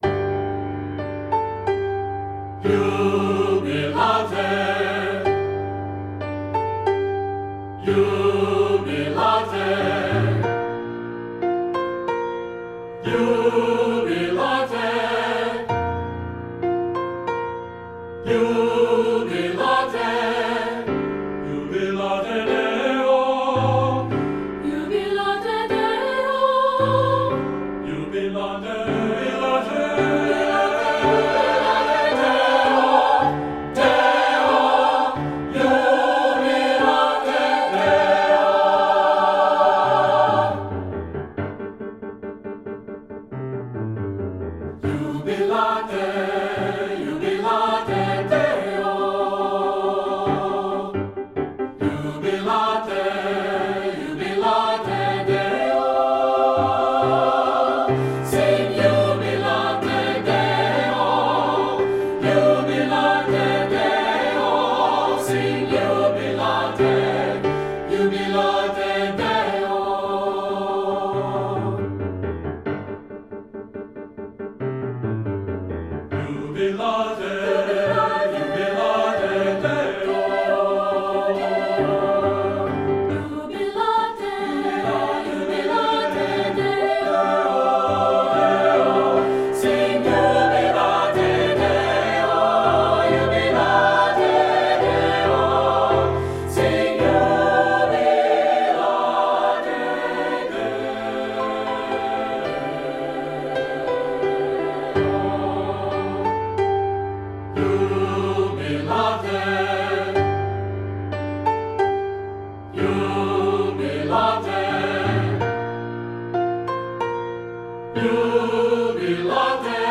secular choral